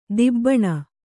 ♪ dibbaṇa